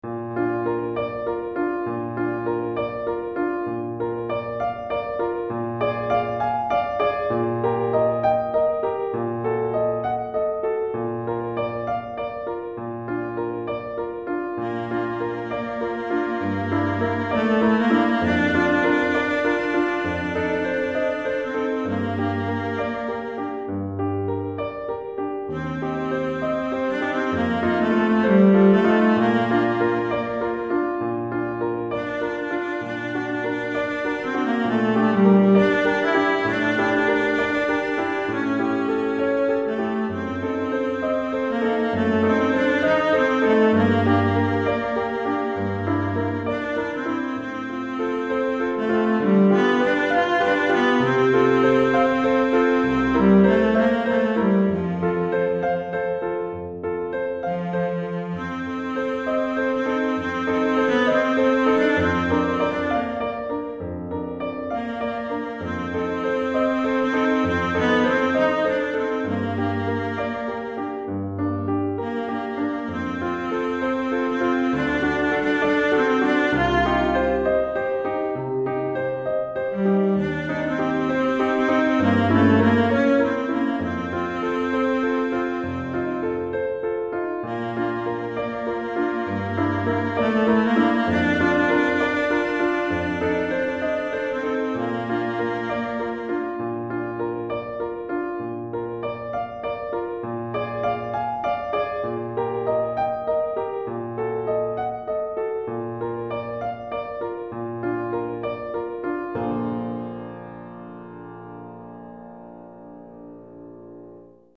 Violoncelle et Piano